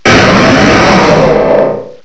sovereignx/sound/direct_sound_samples/cries/aggron_mega.aif at 5119ee2d39083b2bf767d521ae257cb84fd43d0e